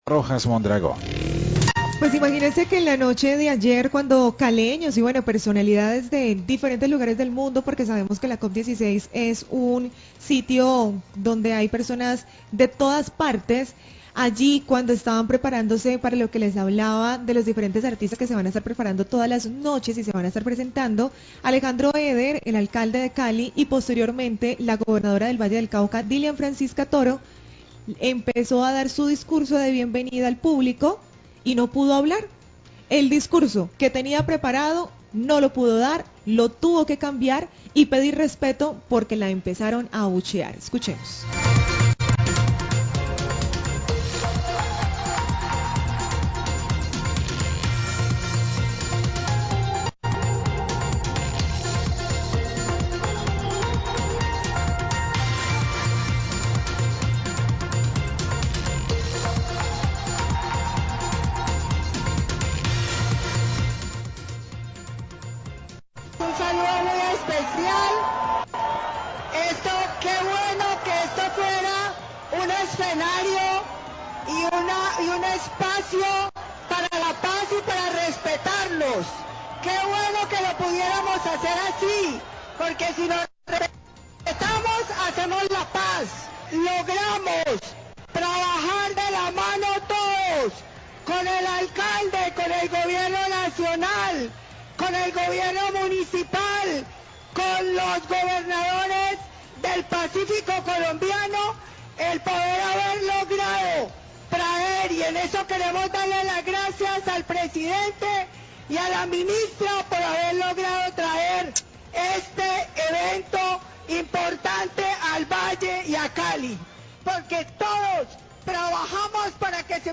Gobernadora del Valle y Alcalde de Cali fueron abucheados en inauguración de la Zona Verde, Voces de Occidente, 1256pm
Radio